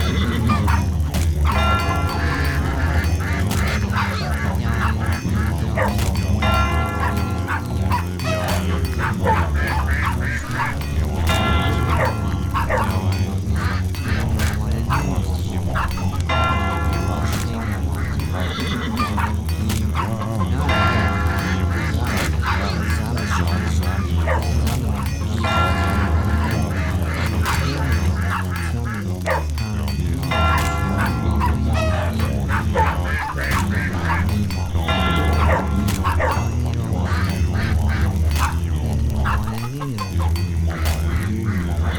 Village_Loop_01.wav